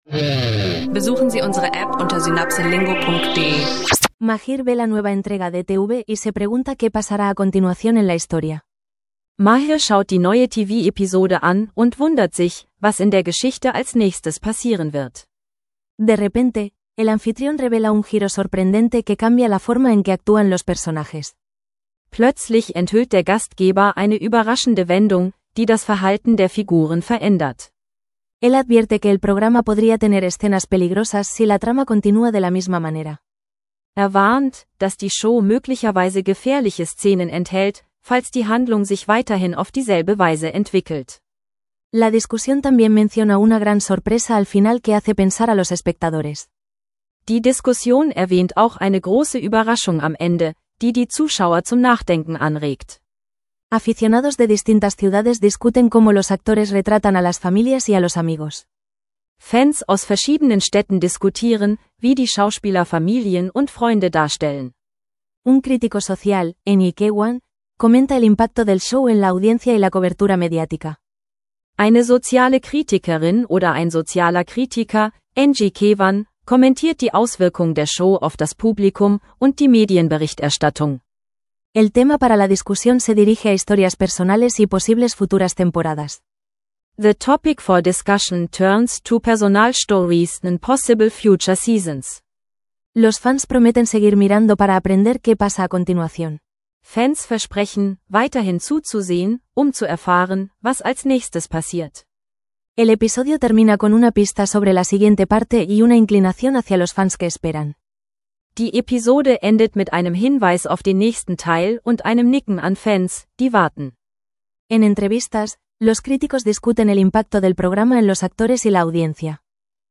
Sprachkurs-Feeling: Mehrsprachige Dialoge, Alltagstaugliches Vokabular und spannende Diskussionen rund um Spanisch lernen – jetzt anhören